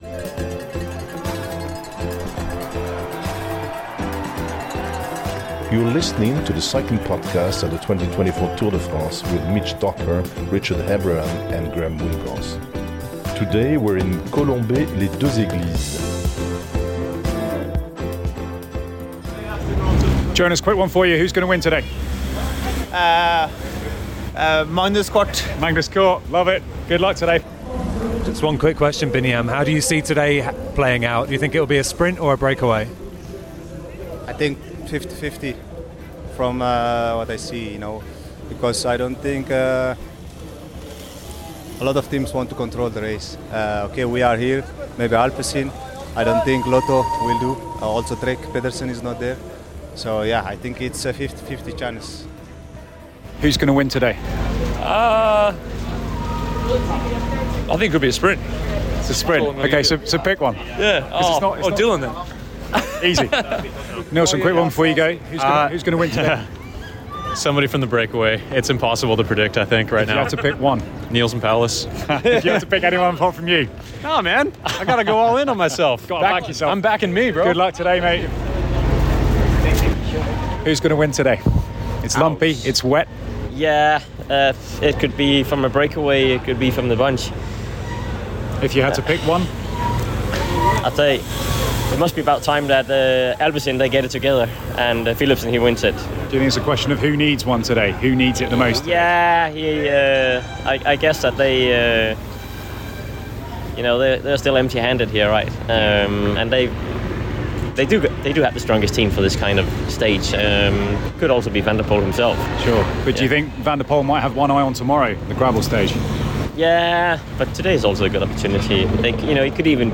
With a new line-up for the 2024 Tour, there’ll be the familiar mix of lively discussion, race analysis, interviews from Outside the Team Bus and some French flavour.